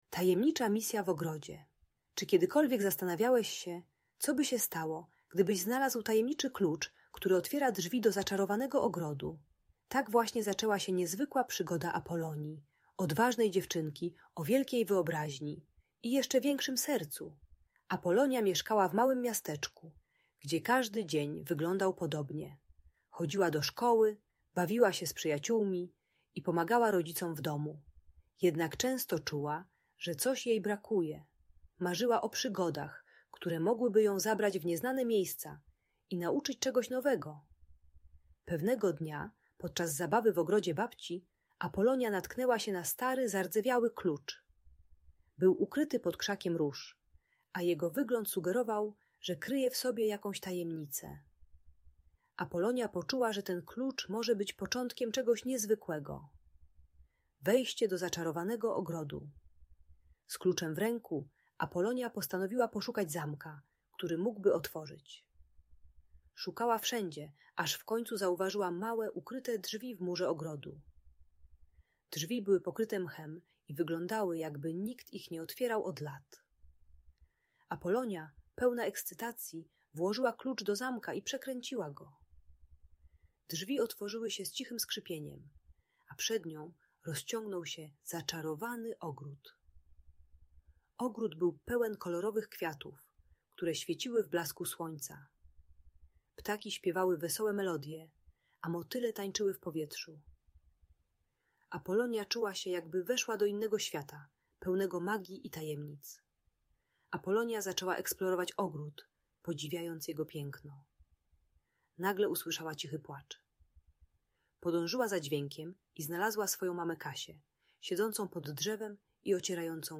Tajemnicza misja w ogrodzie - magiczna opowieść - Audiobajka dla dzieci